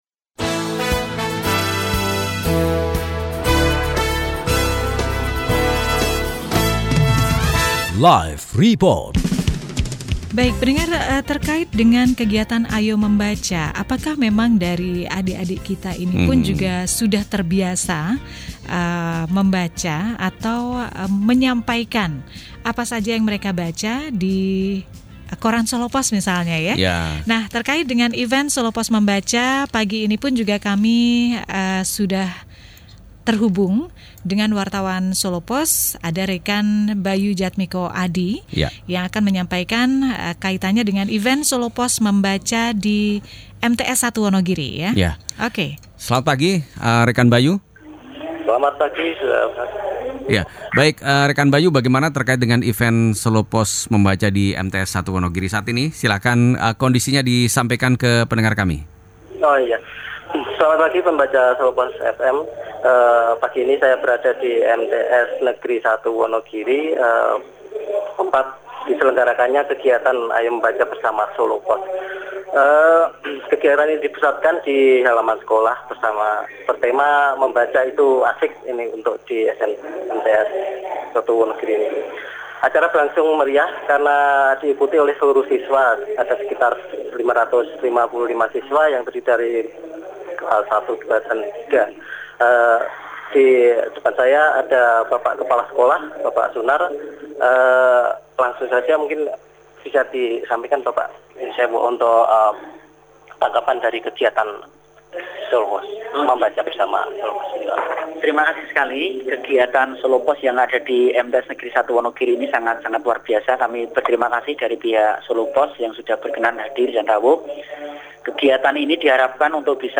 Berikut adalah sekilas reportase “Ayo Membaca Solopos” yang berlangsung pada 02 April 2016 di MTS 1 Wonogiri.